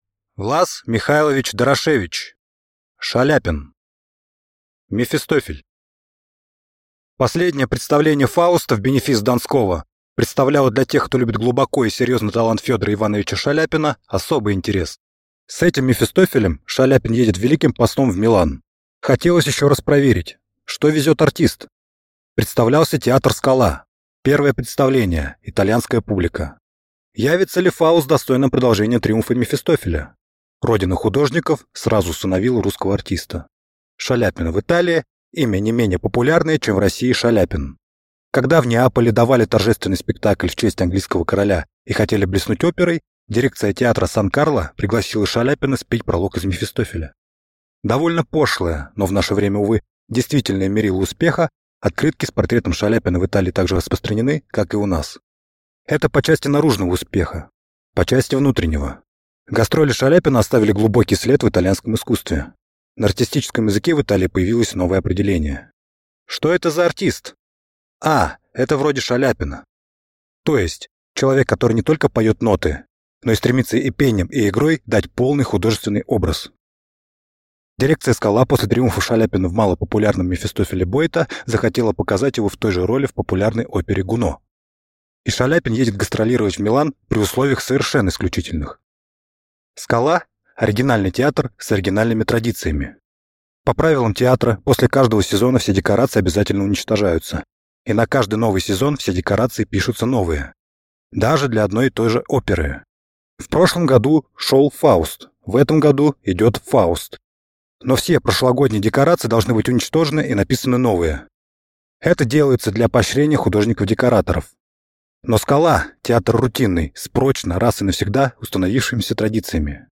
Аудиокнига Шаляпин | Библиотека аудиокниг